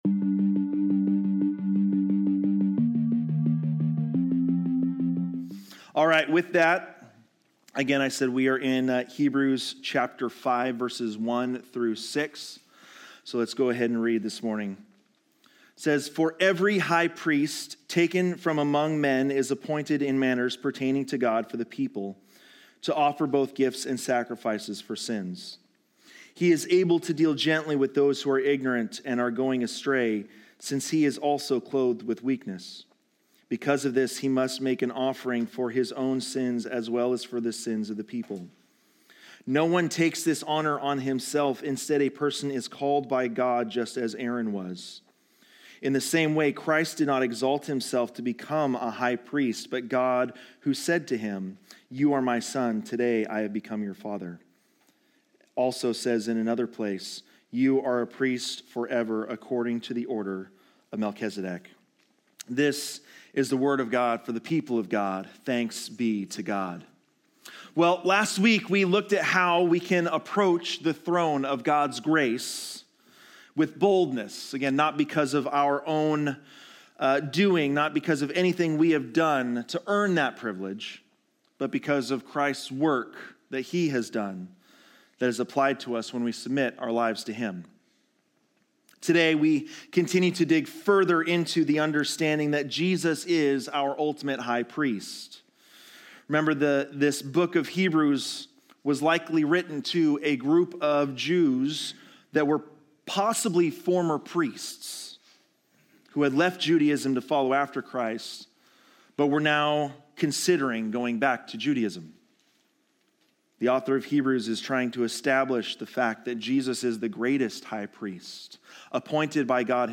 Learn the historical and spiritual meaning of priesthood, the significance of Melchizedek, and how Christ’s humility and obedience invite us to respond with faith. This sermon guides you on your journey to understanding grace, responding to God’s call, and embracing the transformative